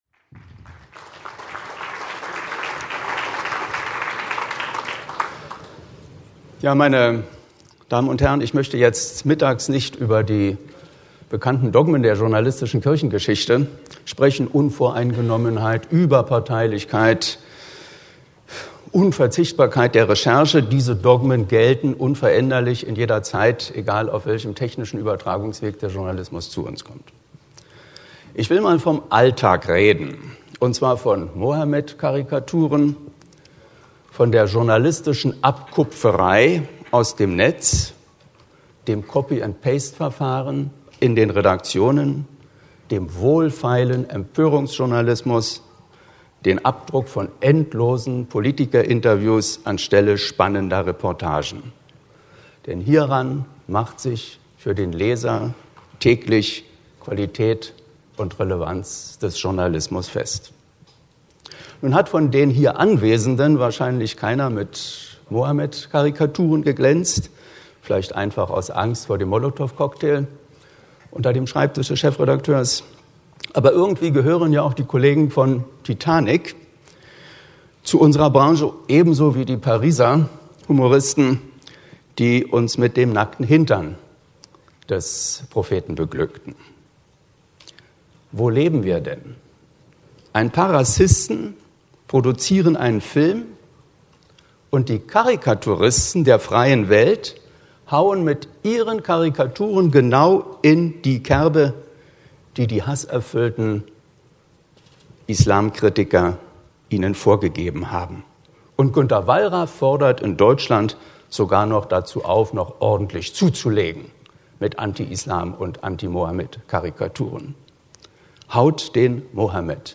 Wer: Prof. Ernst Elitz, Journalist, u. a. Intendant des Deutschlandradios 1994-2009 Was: Keynote, BDZV-Zeitungskongress 2012 Wo: Berlin, Maritim proArte Hotel Wann: 25.09.2012, 11:48-11:58 Uhr